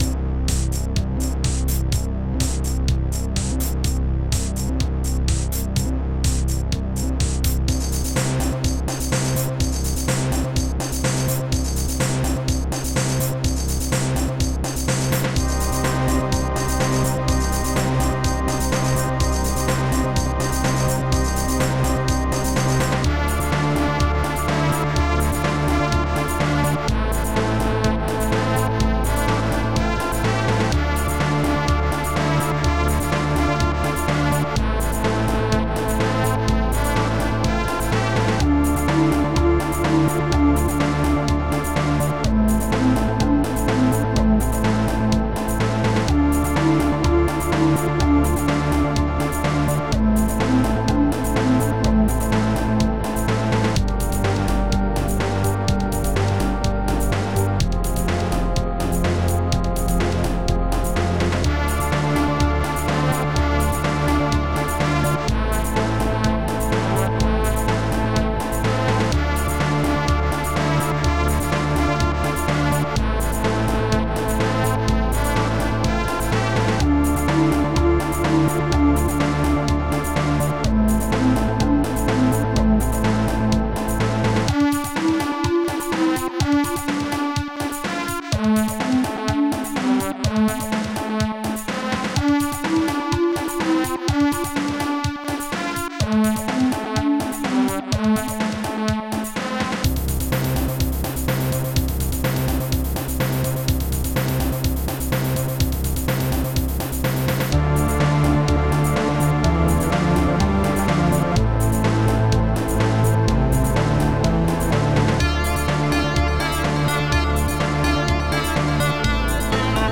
st-13:fif-gdrum2
st-11:ip-cymbal
st-11:ip-snare3
st-10:ir-desertflute
st-10:ir-deserttrumpet